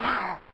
scrump_shot.ogg